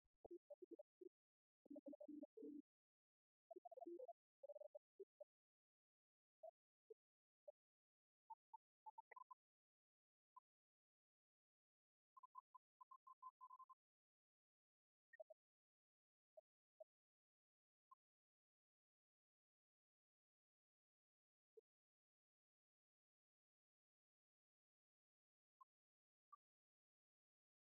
enregistrement sur 78t. lors de la kermesse du 14 mai 1950
musique, ensemble musical